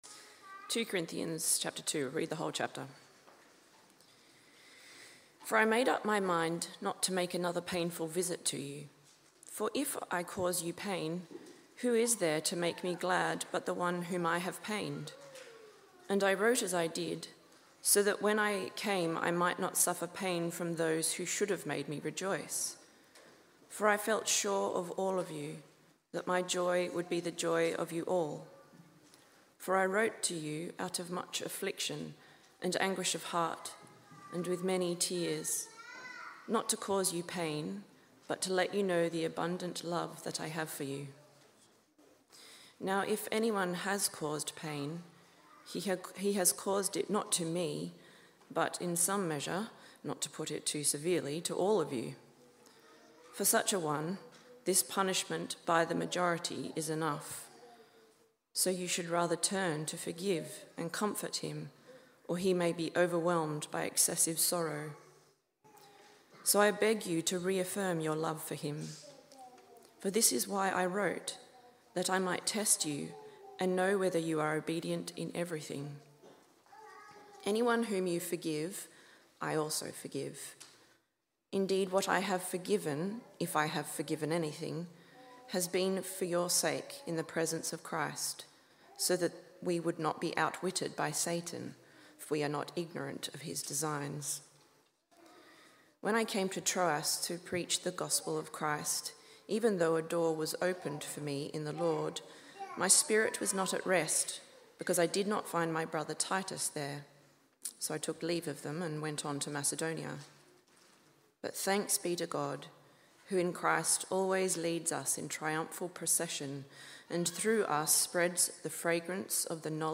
Biblical, expository sermons from Trinity Church Tamworth that aim to bring glory to God, as we honour, enjoy, and declare the supremacy of Jesus Christ over all things.